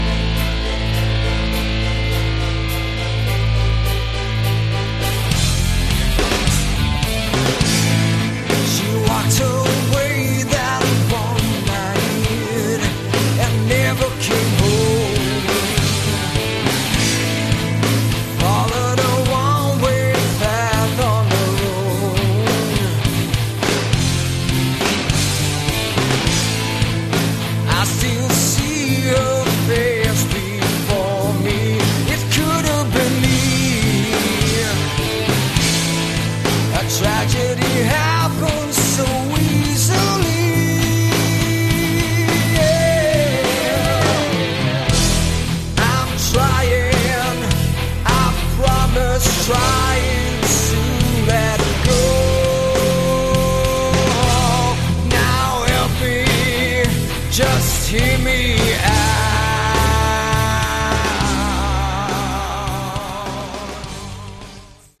Category: Melodic Prog Rock
electric and acoustic guitars
keyboards, backing vocals
drums, backing vocals